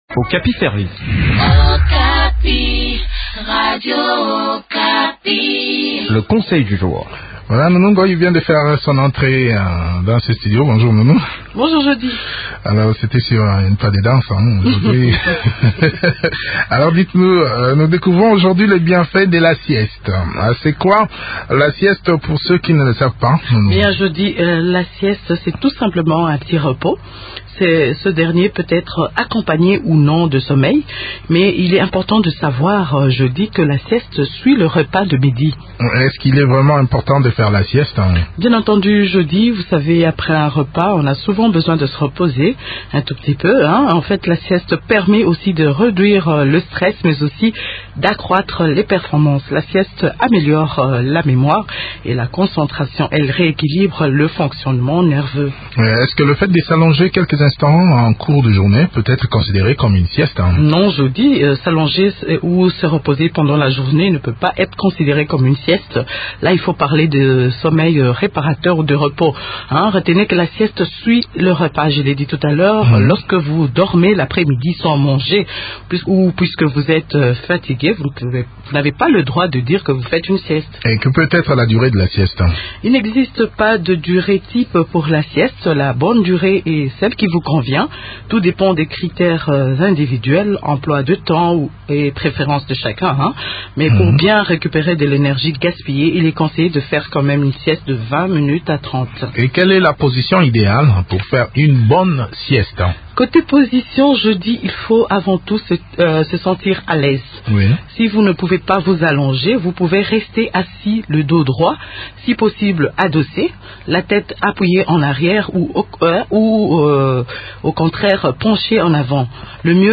La sieste permet non seulement de réduire le stress mais aussi d’accroître les performances physiques. Des détails dans cette interview